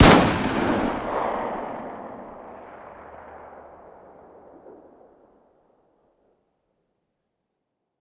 fire-dist-357sig-pistol-ext-06.ogg